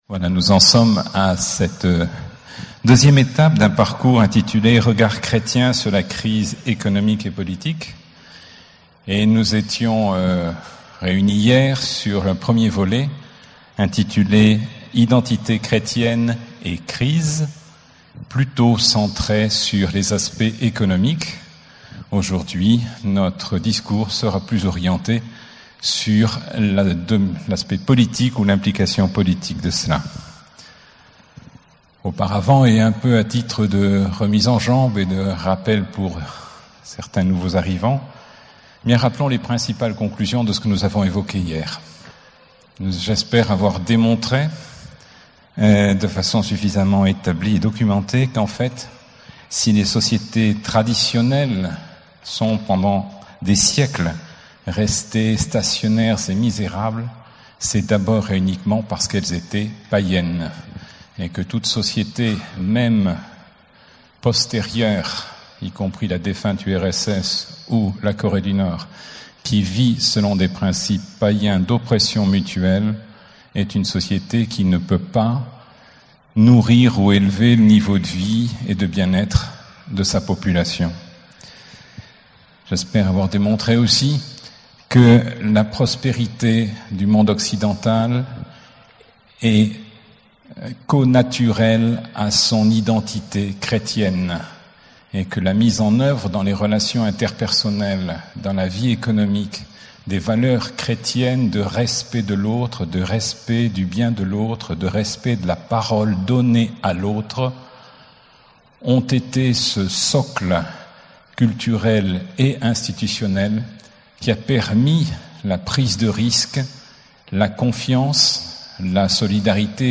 Enseignement
Session 25 - 35 ans : L'Amour me presse (14 au 18 juillet 2012)